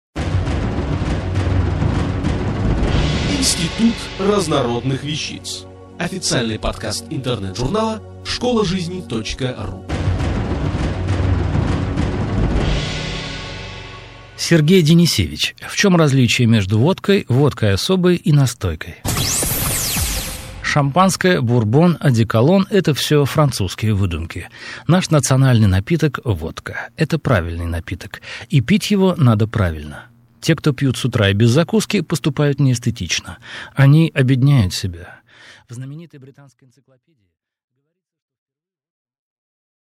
Аудиокнига В чем различие между водкой, водкой особой и настойкой?